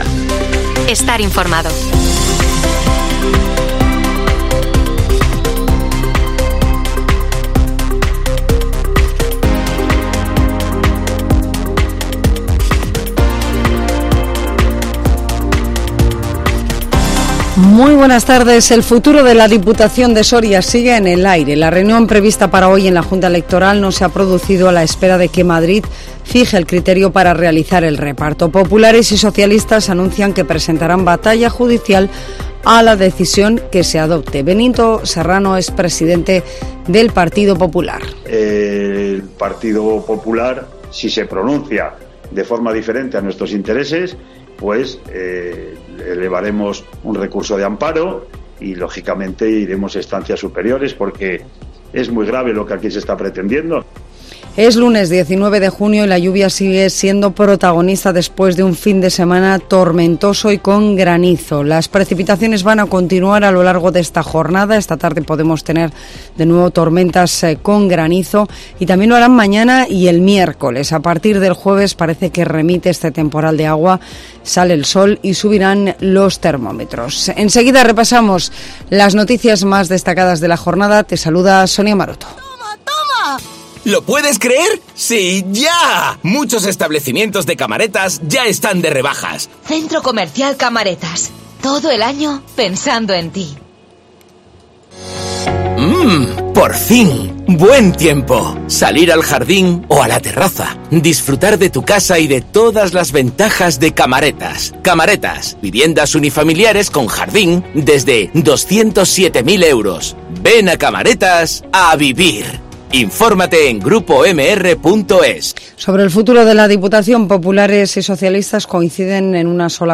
INFORMATIVO MEDIODÍA COPE SORIA 19 JUNIO 2023